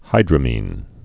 (hīdrə-mēn)